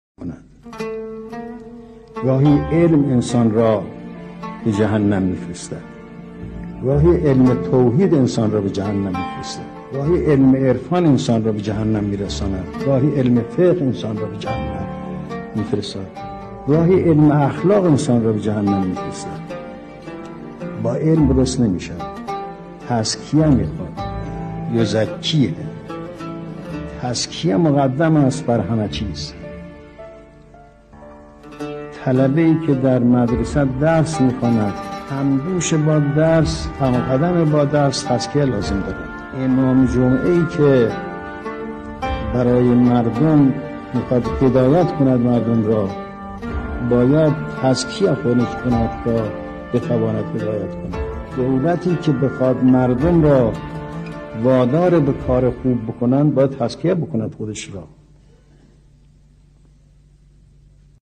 به گزارش خبرگزاری حوزه، گزیده ای از بیانات حضرت امام، بنیان‌گذار کبیر انقلاب اسلامی در موضوع «تزکیه و تهذیب» تقدیم شما فرهیختگان می شود.